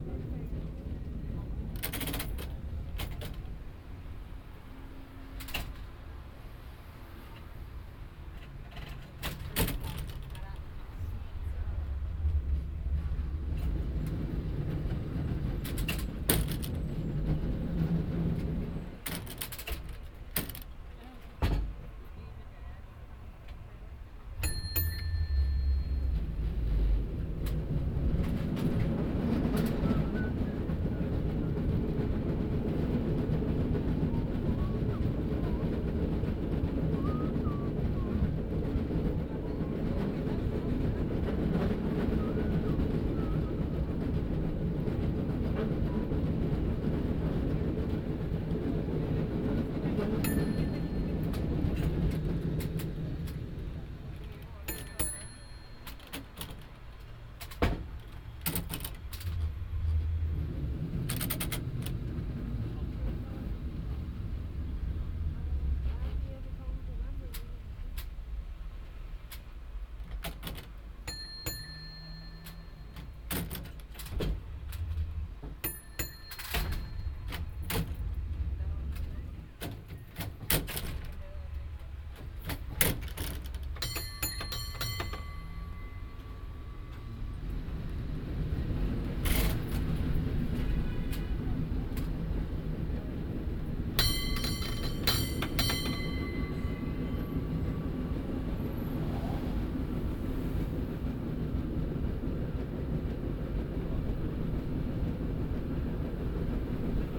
Cable Car San Francisco
bell binaural cable car ding field-recording Francisco San sound effect free sound royalty free Sound Effects